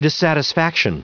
Prononciation du mot dissatisfaction en anglais (fichier audio)
Prononciation du mot : dissatisfaction